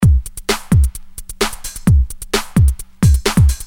Electro rythm - 130bpm 31